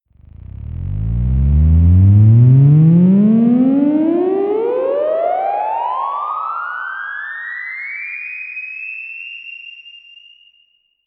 slim theremin
Zero-C7 scale.
Sample (reverb added).